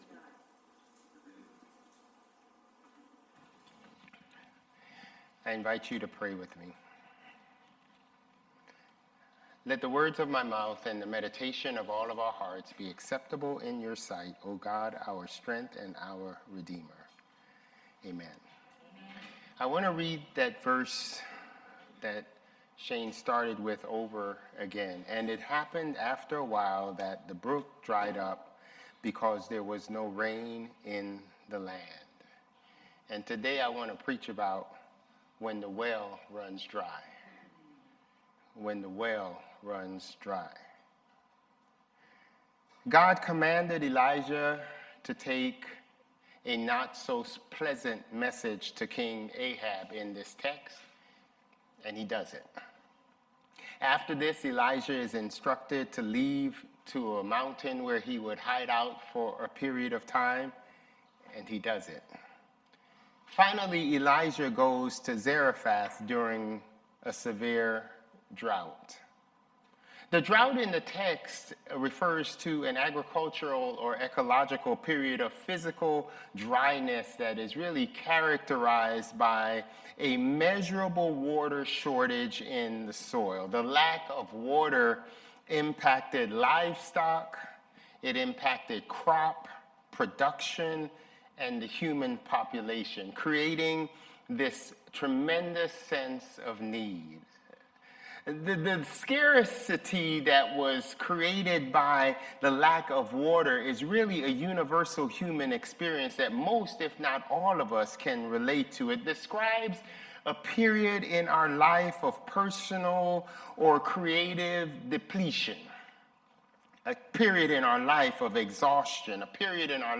September 7 Worship